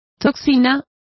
Complete with pronunciation of the translation of toxin.